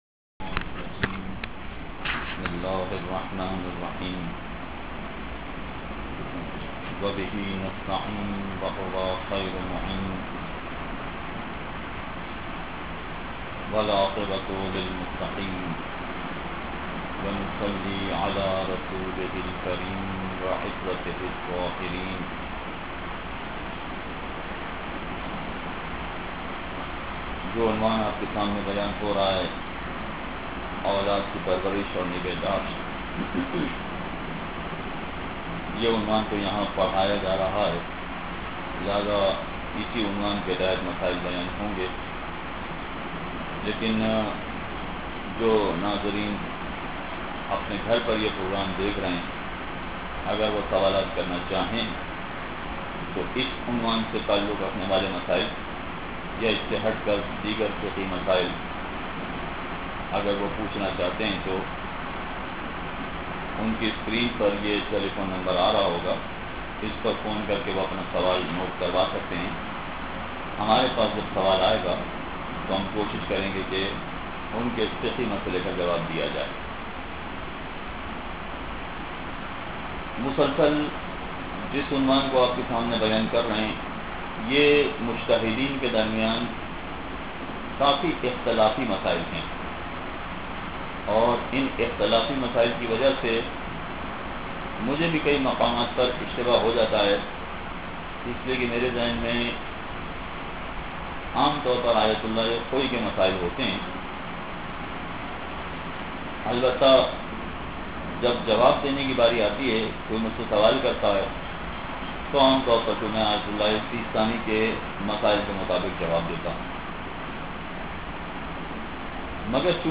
at Quran o itrat Academy